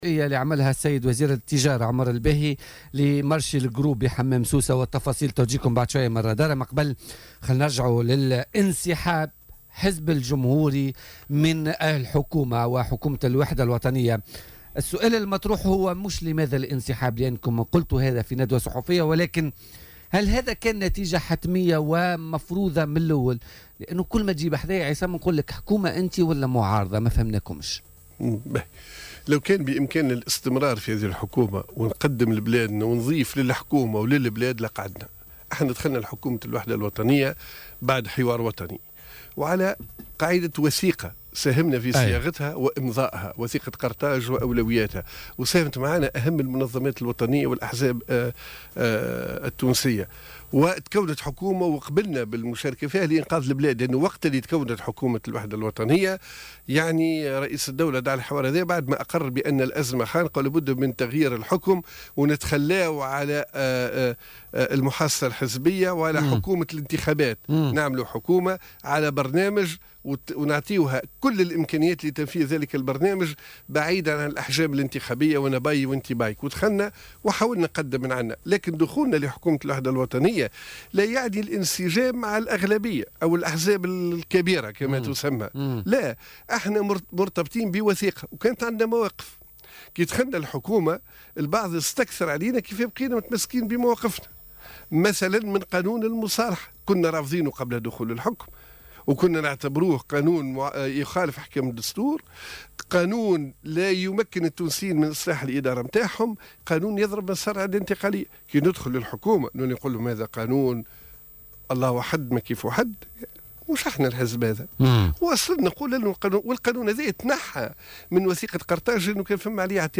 وقال ضيف "بوليتيكا" على "الجوهرة اف أم" إن إياد الدهماني تعرض لضغوطات من نداء تونس للاختيار بين الحكومة وحزب الجمهوري.